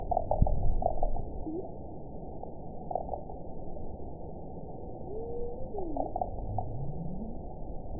event 922345 date 12/30/24 time 01:36:47 GMT (11 months ago) score 9.54 location TSS-AB03 detected by nrw target species NRW annotations +NRW Spectrogram: Frequency (kHz) vs. Time (s) audio not available .wav